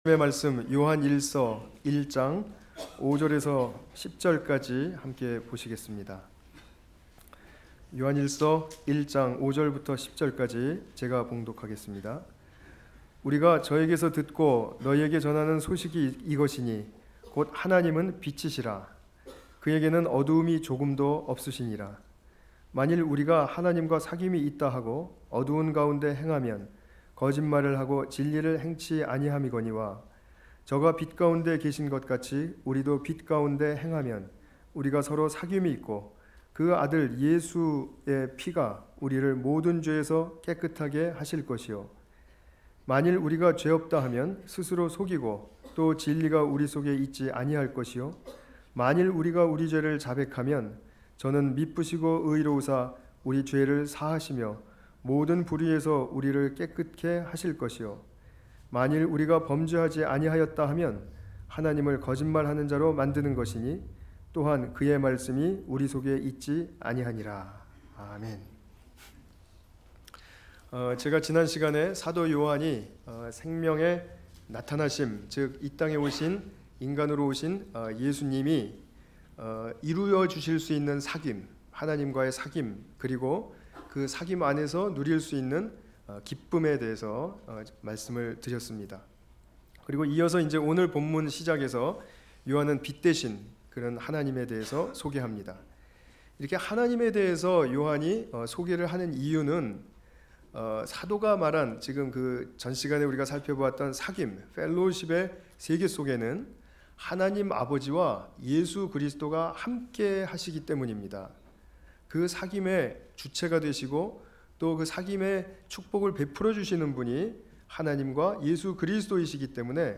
요한일서 1장 5-10절 관련 Tagged with 주일예배